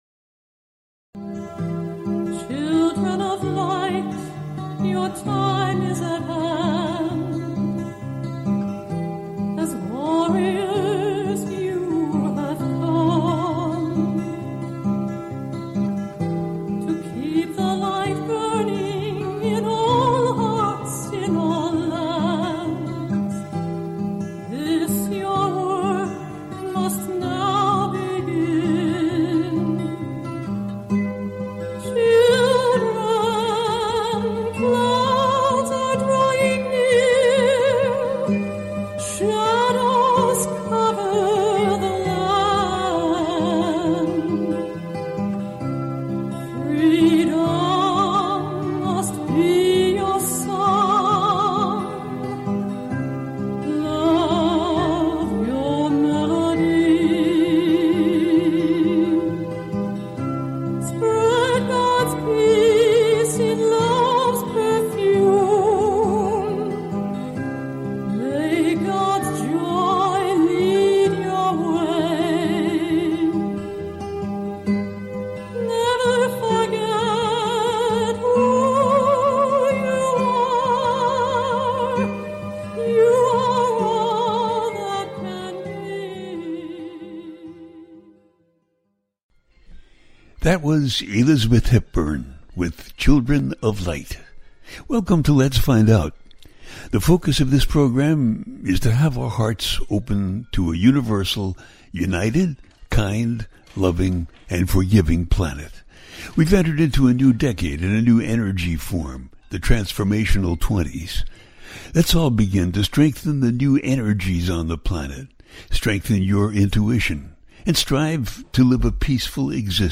Talk Show
The listener can call in to ask a question on the air.
Each show ends with a guided meditation.